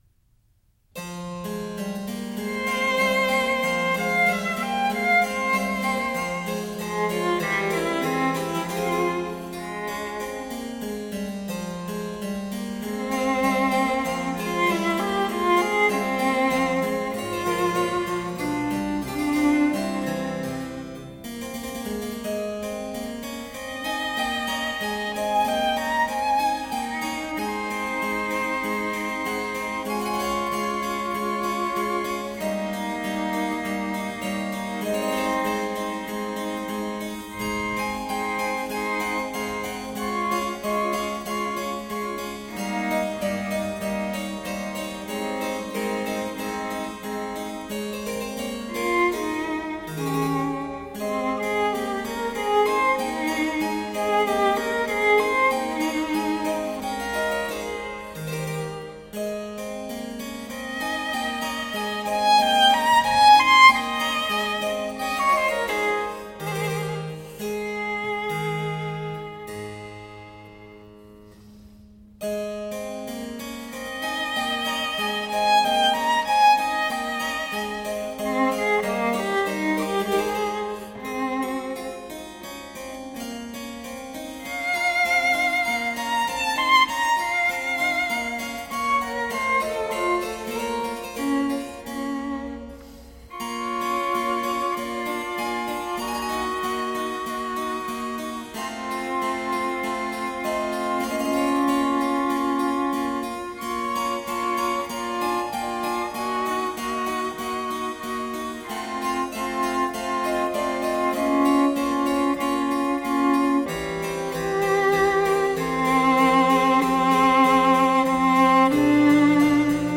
Sonata in F
Andante